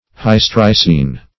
Search Result for " hystricine" : The Collaborative International Dictionary of English v.0.48: Hystricine \Hys"tri*cine\ (h[i^]s"tr[i^]*s[i^]n), a. [See Hystrix .]